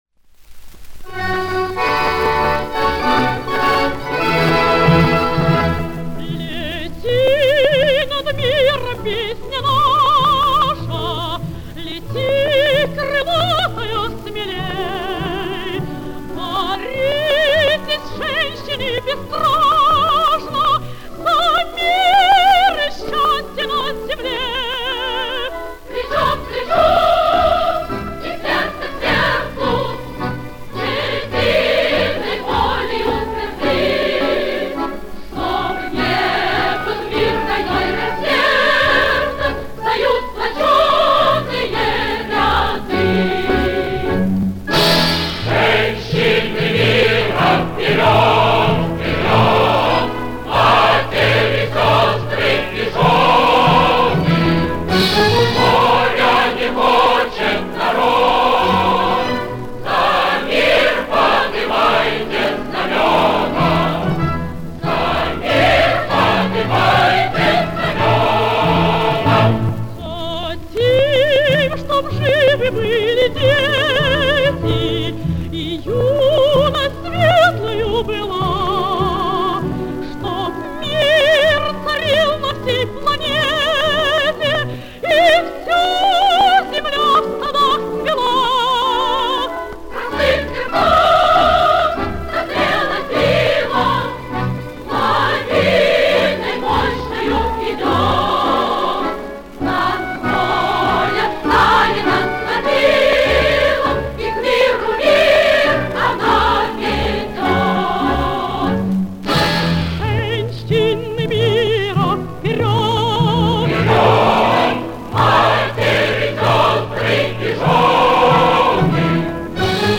Источник грампластинка